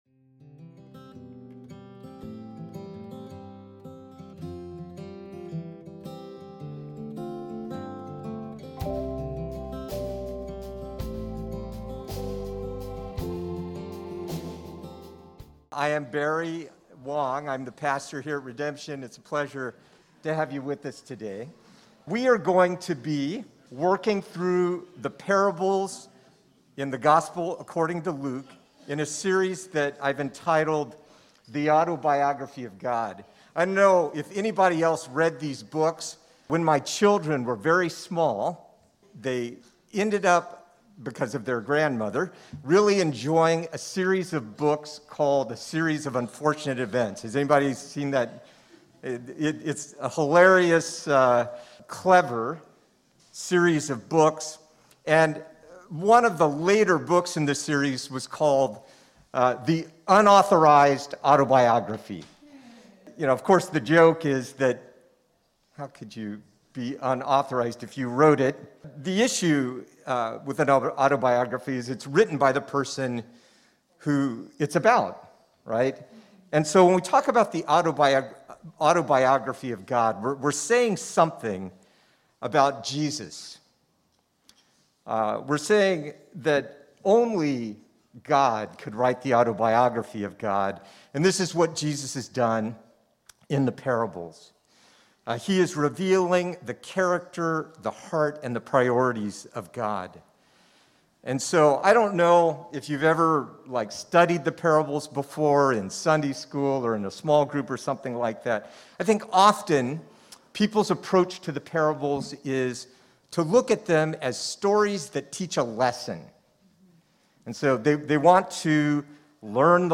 This message kicks off our new sermon series entitled The Autobiography of God based on the parables in Luke. In this message we focus on Luke 15: 1-24 and the parable of the lost son. We look at the actions of both the father and the son and ask the question who is the real prodigal?